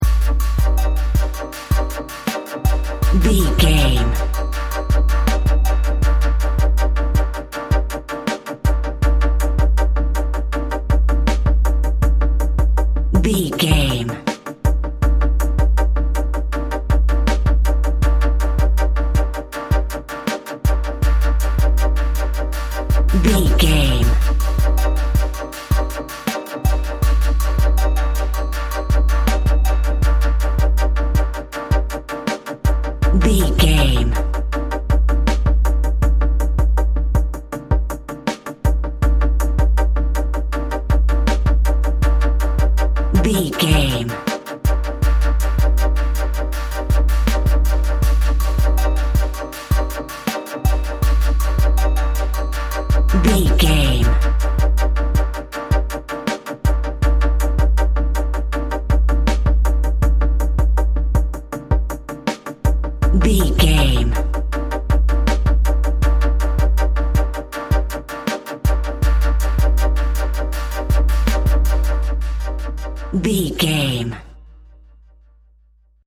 Atonal
groovy
smooth
high tech
futuristic
hypnotic
drum machine
synthesiser
electronic
techno
trance
synth drums
synth leads
synth bass